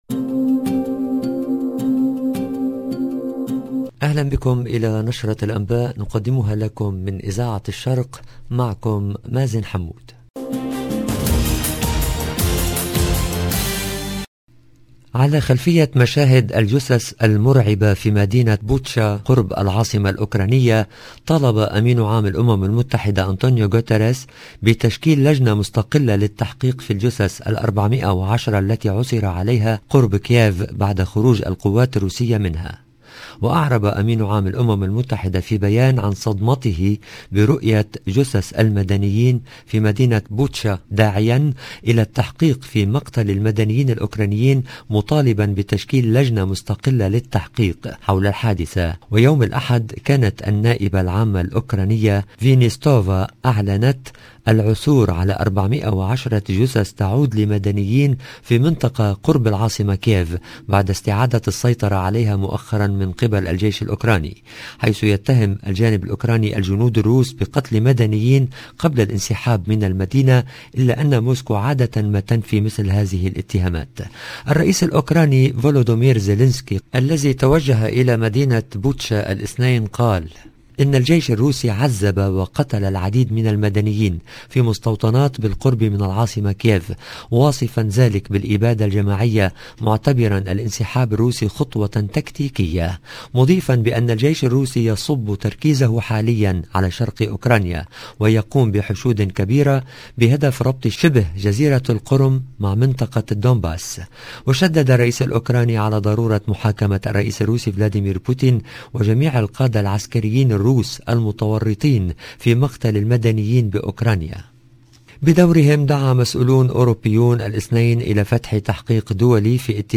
LE JOURNAL DU SOIR EN LANGUE ARABE DU SOIR DU 4/04/22